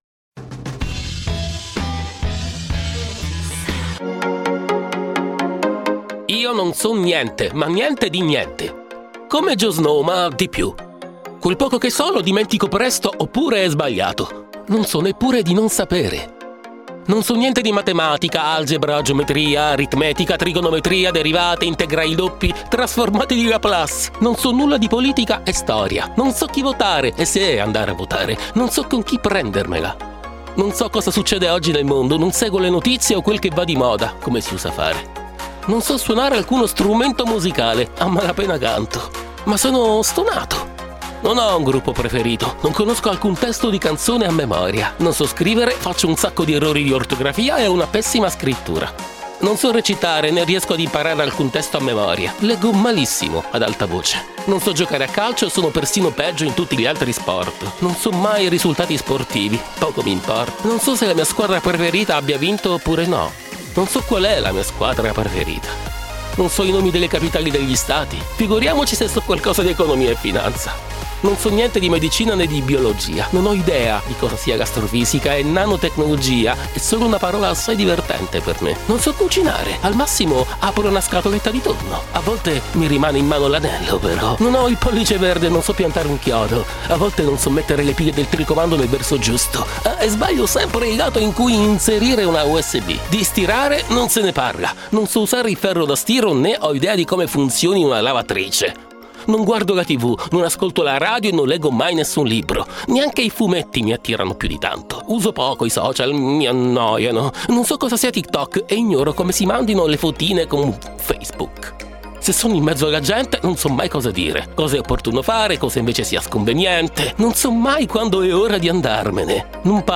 Vishmak: “Moonlight” – Royalty Free Music – YouTube